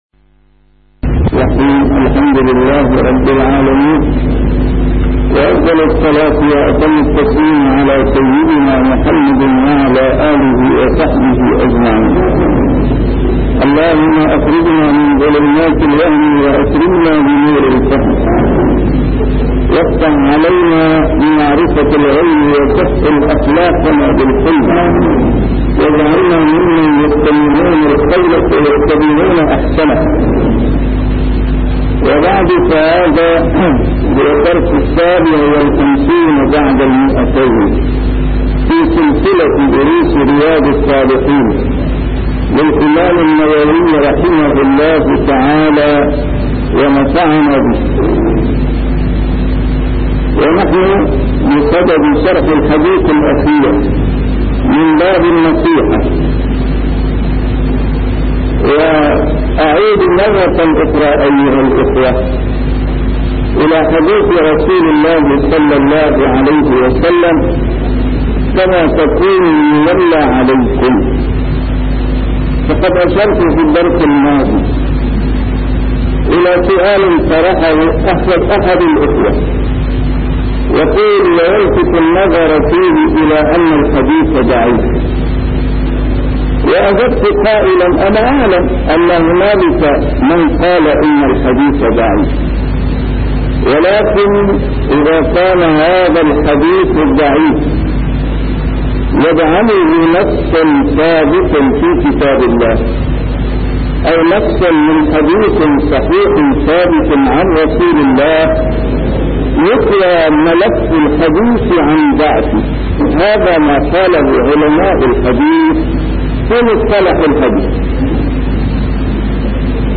A MARTYR SCHOLAR: IMAM MUHAMMAD SAEED RAMADAN AL-BOUTI - الدروس العلمية - شرح كتاب رياض الصالحين - 257- شرح رياض الصالحين: النصيحة